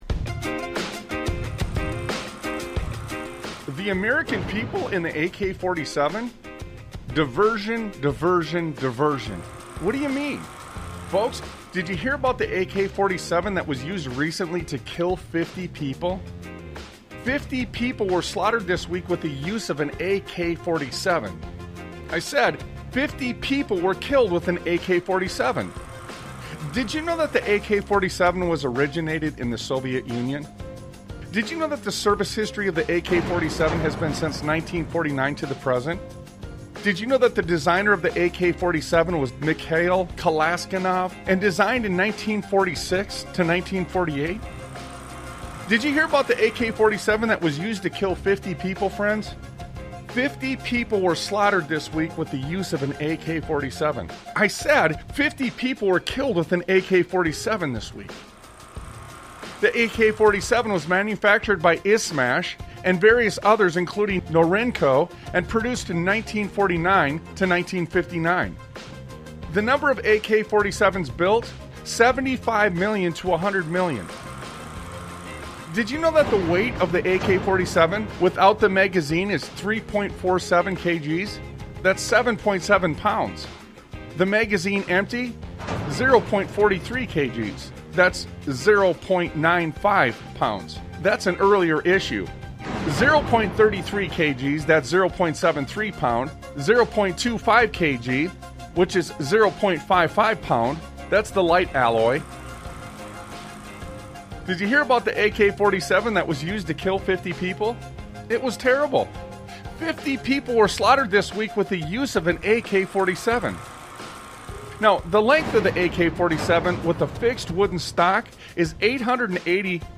Talk Show Episode, Audio Podcast, Sons of Liberty Radio and Foretelling Exposed After The Fact on , show guests , about Foretelling Exposed After The Fact, categorized as Education,History,Military,News,Politics & Government,Religion,Christianity,Society and Culture,Theory & Conspiracy